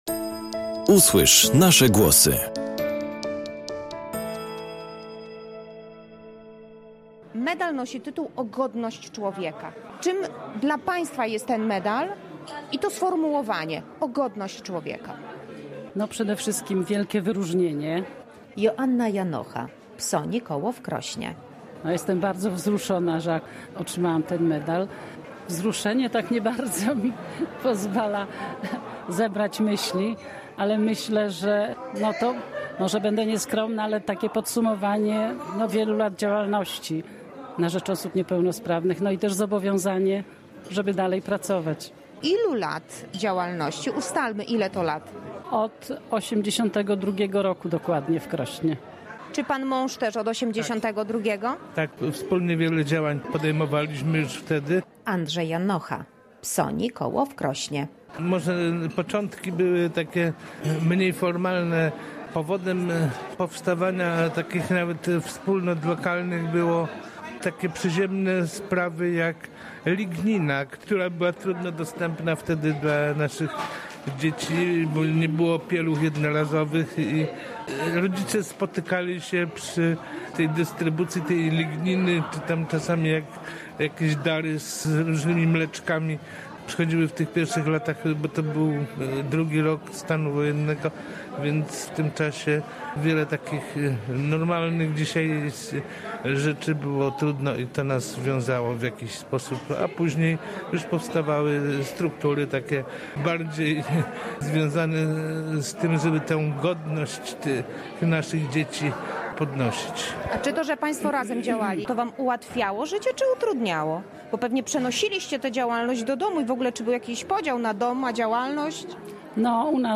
Przypomnijmy rozmowę jaką wtedy w Warszawie przeprowadziła z nimi